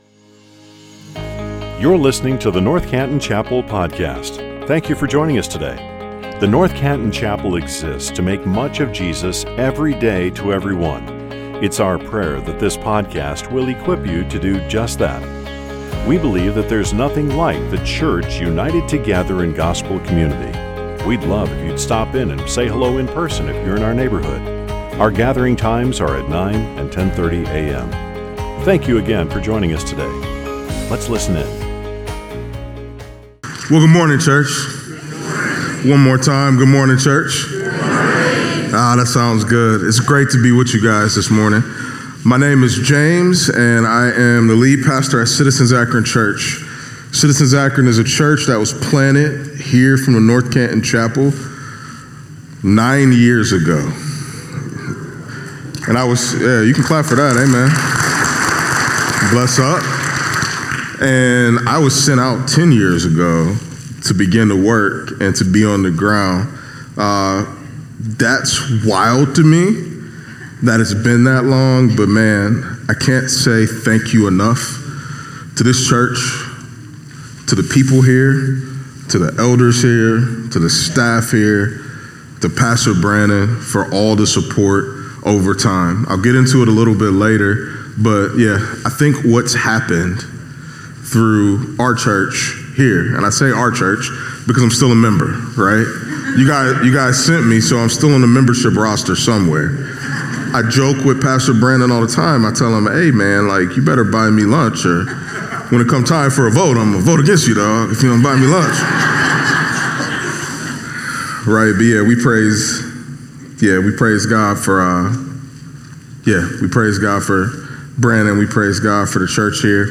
Why does a church do what it does?What beliefs drive its decision-making?What's really important to us, at NCC?Join us for this six-week teaching series-simply titled